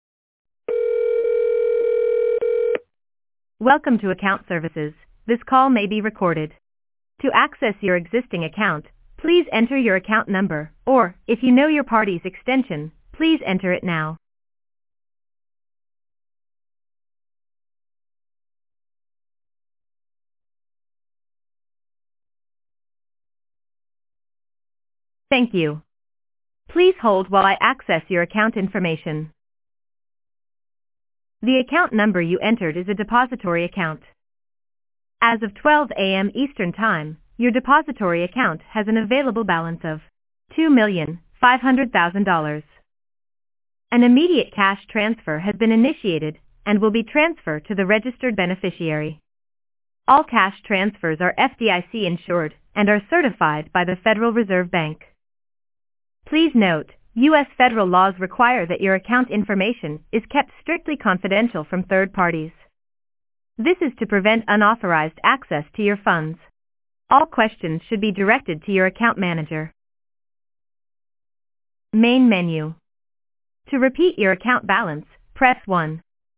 Scams Robo Calls